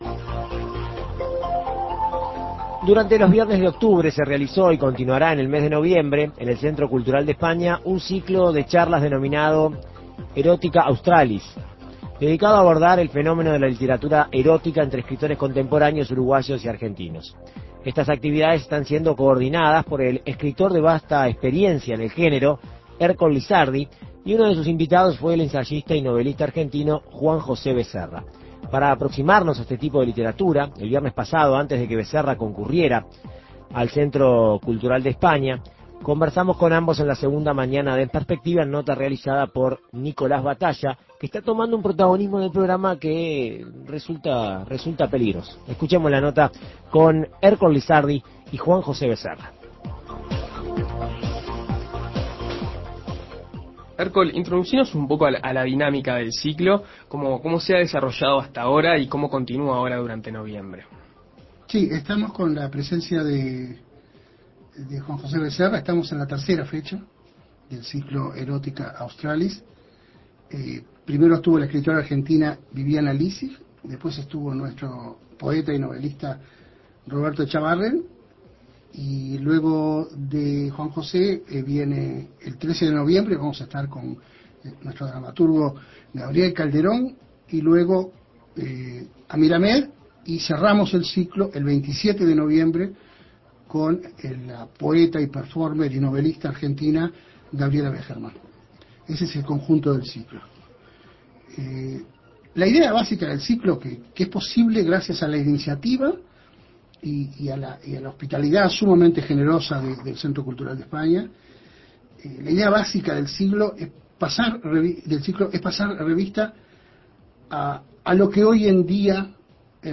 En Perspectiva Segunda Mañana dialogó con ambos para conocer de cerca el género.